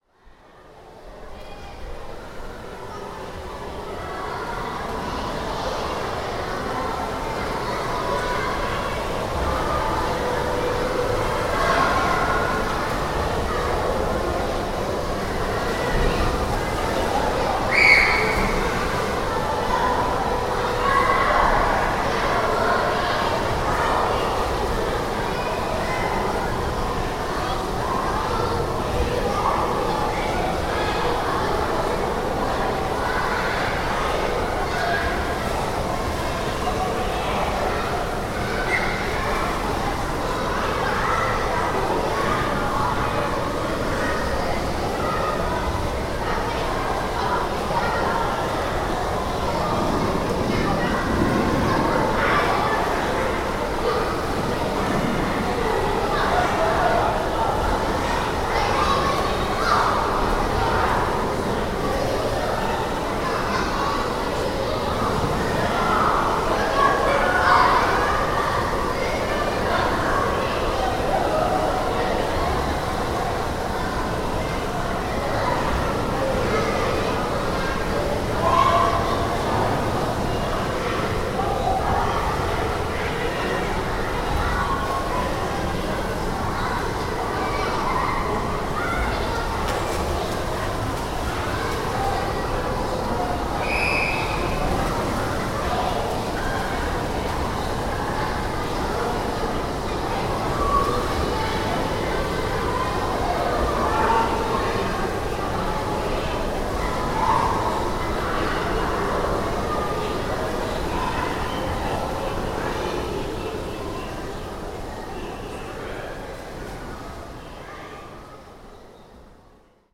The pool at The Bridge, Easterhouse, Glasgow. 1.30pm, 5 Feb 2011.